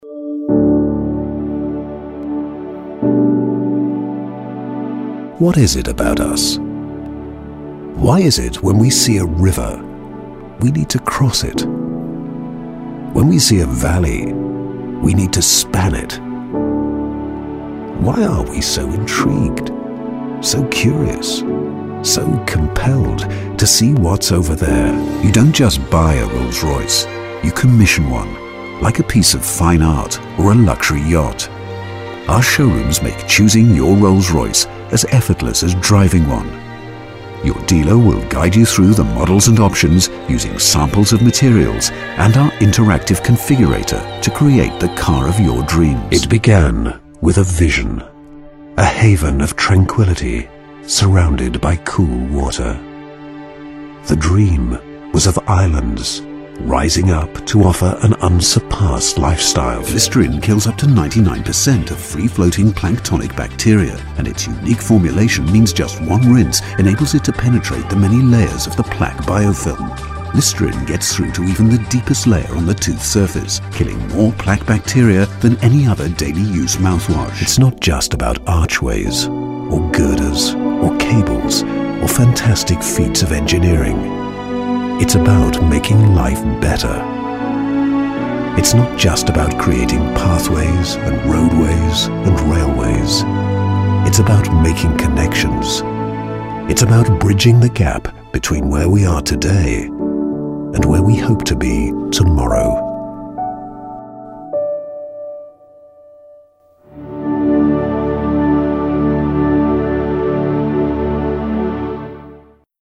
Narração
Minha voz é geralmente descrita como calorosa, natural e distinta e é frequentemente usada para adicionar classe e sofisticação a projetos de publicidade e narrativa.